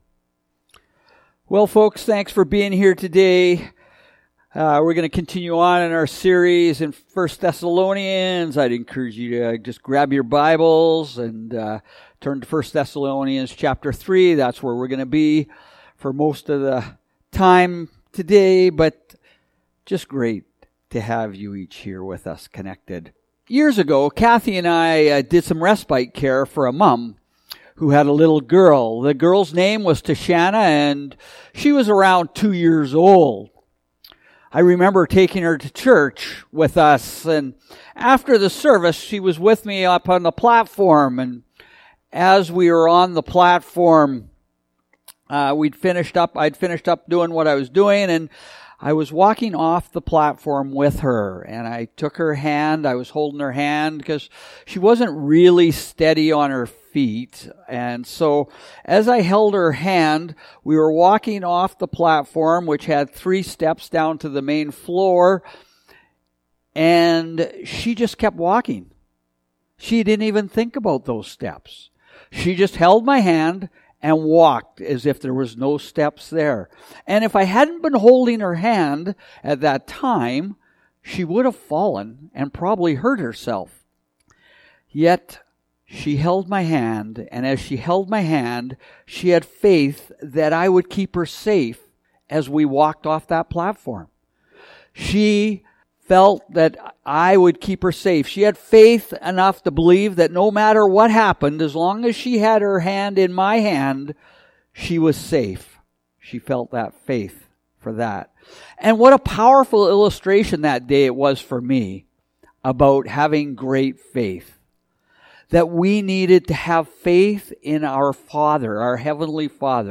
Sermons | Cross Roads Pentecostal Assembly